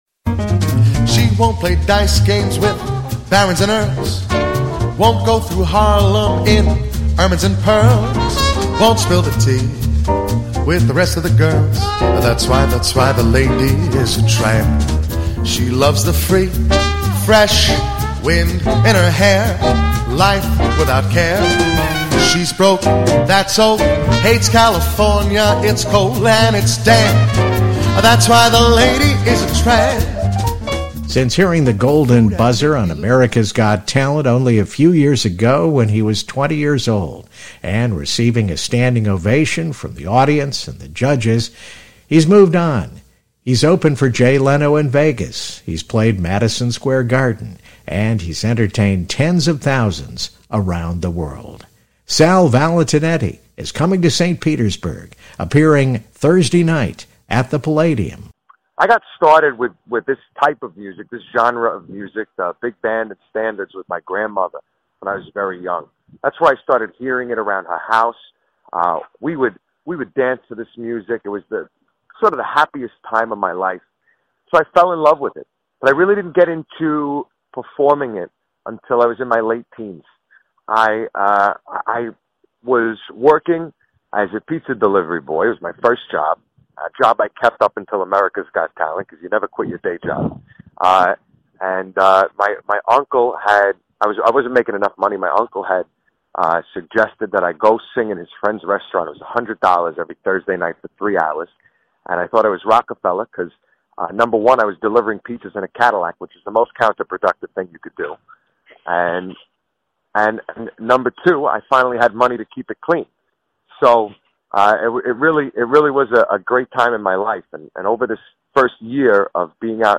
America's Got Talent Star Sal Valentinetti at Palladium 1-18-24 RadioStPete Interview